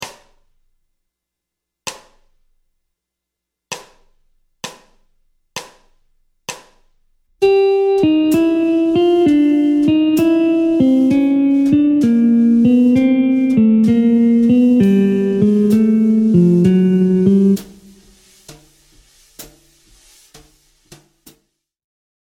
Mode Mixolydien ( V7 gamme Majeure)
Descente de gamme
Gamme-bop-desc-Pos-42-C-Mixo.mp3